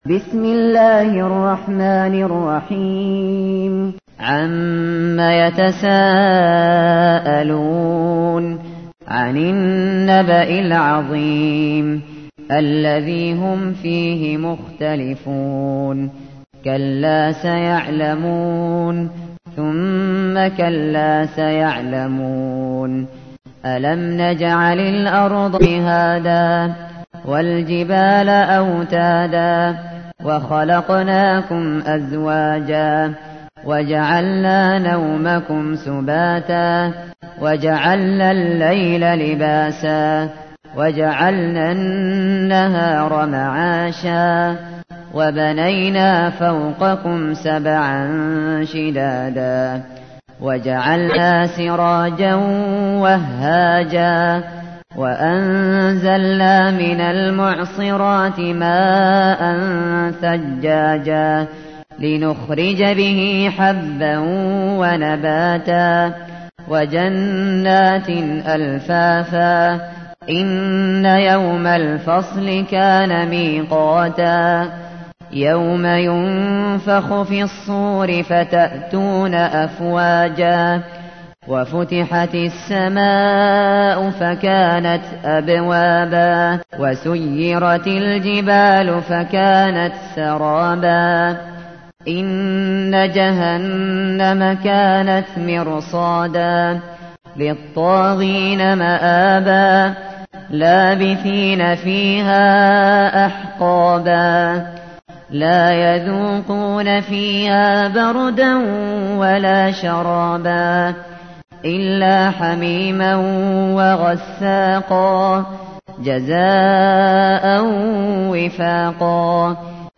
تحميل : 78. سورة النبأ / القارئ الشاطري / القرآن الكريم / موقع يا حسين